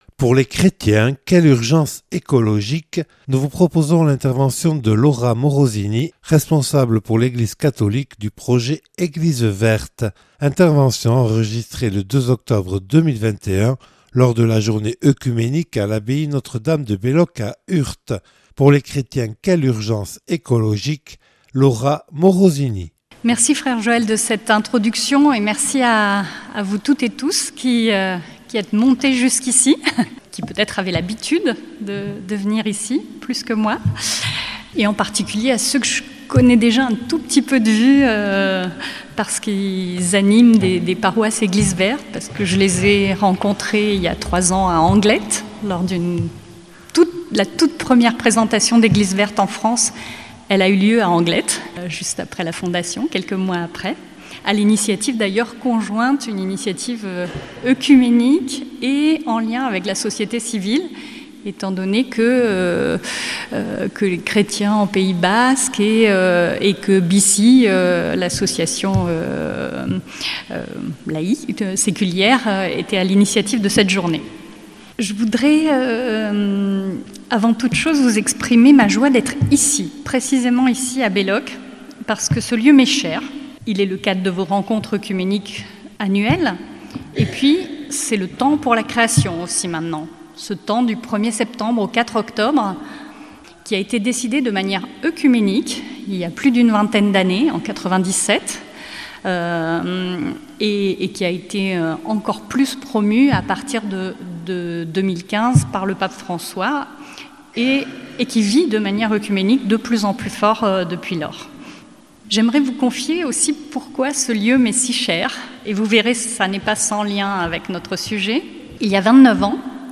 (Enregistrée le 02/10/2021 lors de la Journée œcuménique à l’abbaye Notre-Dame de Belloc à Urt).